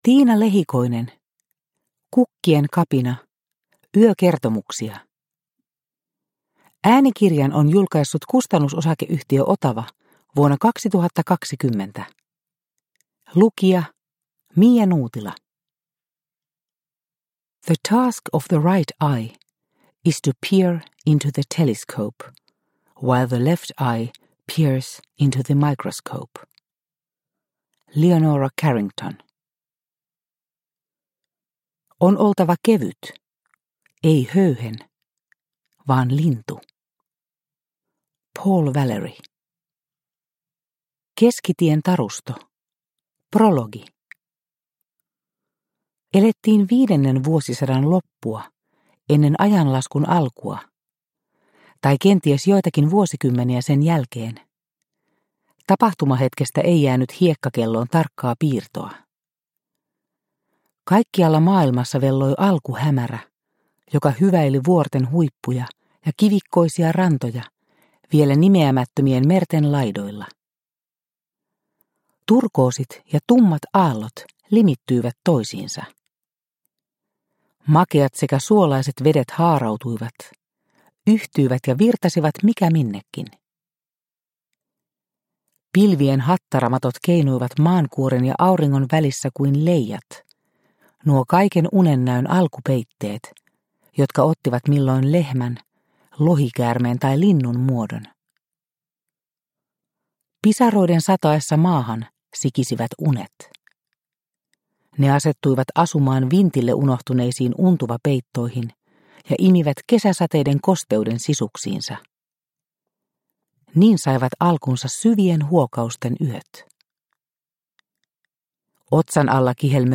Kukkien kapina – Ljudbok – Laddas ner